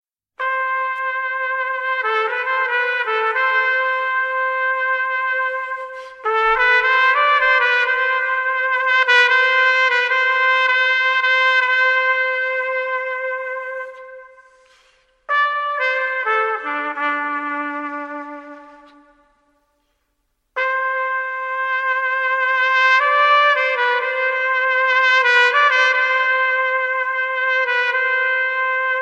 The Whistle